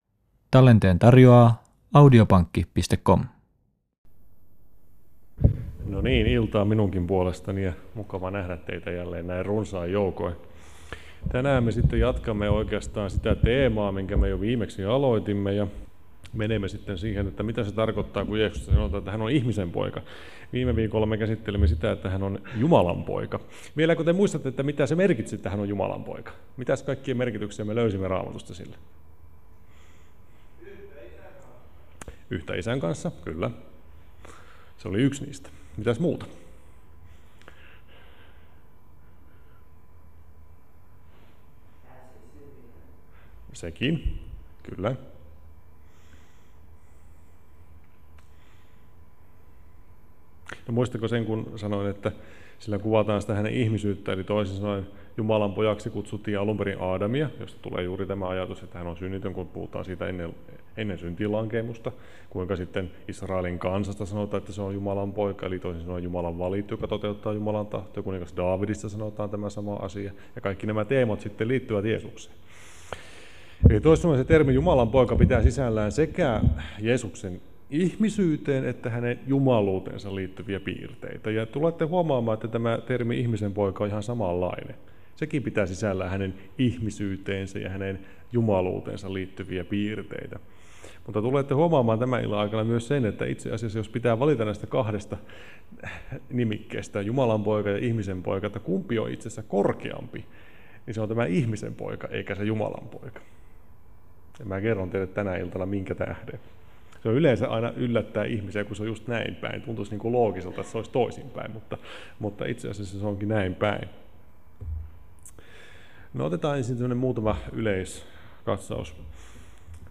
Luentosarja: Jeesuksen ihmeellinen elämä. Oletko kiinnostunut oppimaan neljän evankeliumin sanomasta ja Jeesuksen opetuksista?